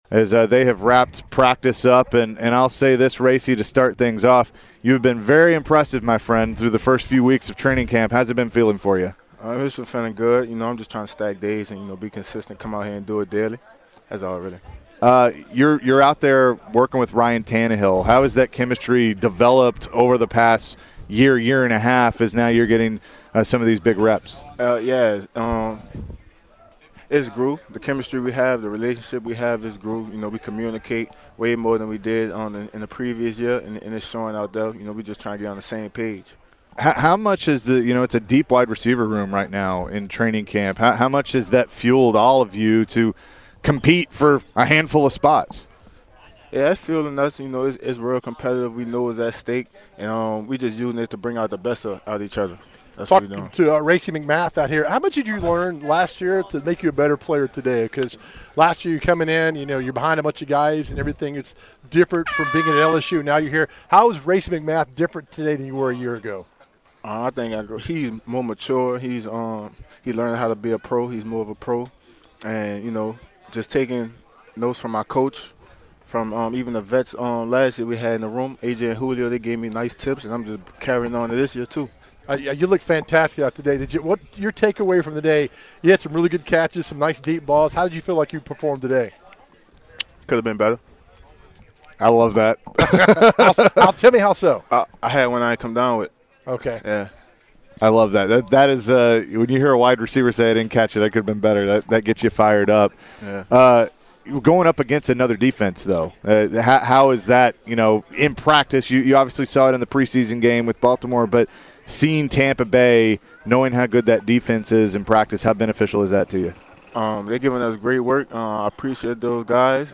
Titans WR Racey McMath interview (8-17-22)